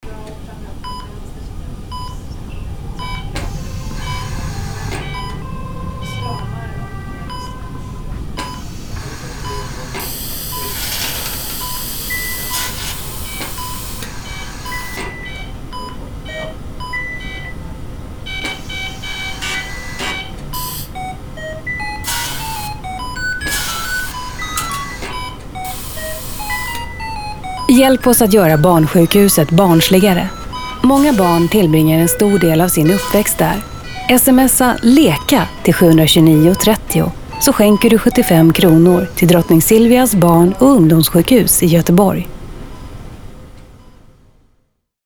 RADIOREKLAM
Ta tråkiga, allvarliga ljud man hör hela tiden på ett sjukhus och gör om dem till något som barn blir glada av.